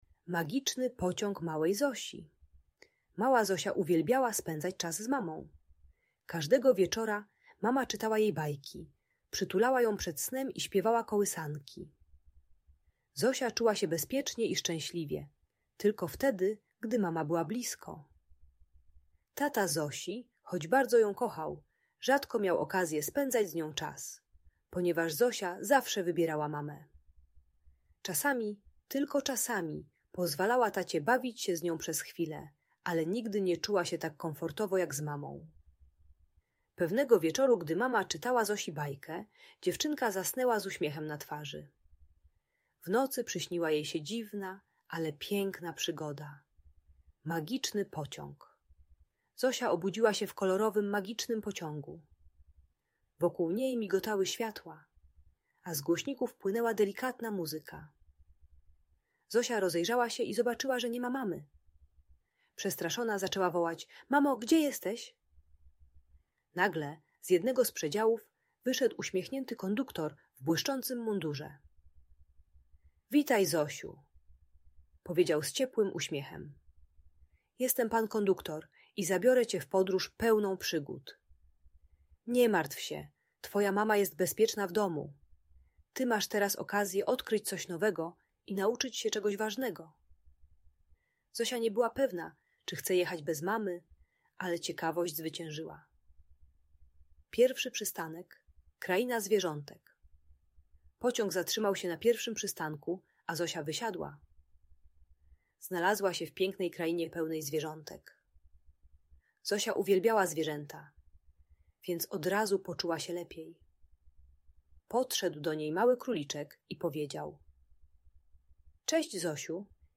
Magiczny Pociąg Małej Zosi - Przywiązanie do matki | Audiobajka